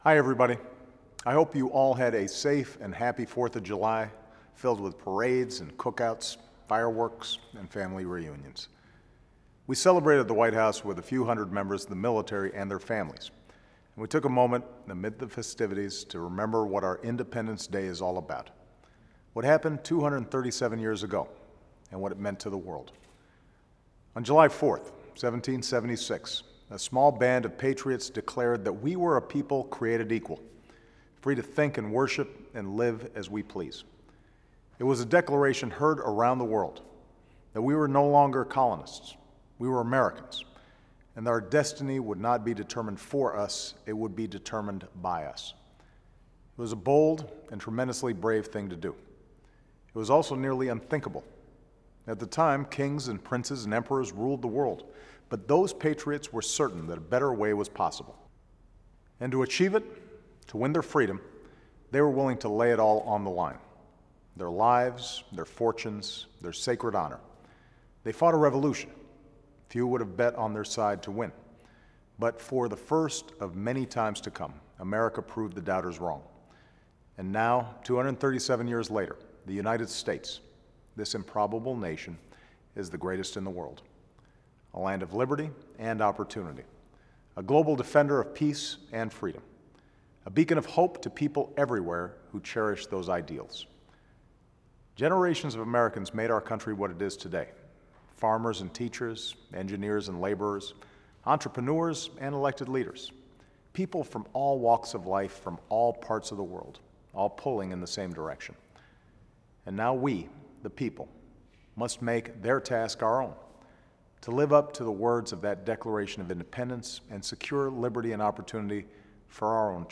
Weekly Address: Celebrating Independence Day